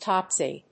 /ˈtɑpsi(米国英語), ˈtɑ:psi:(英国英語)/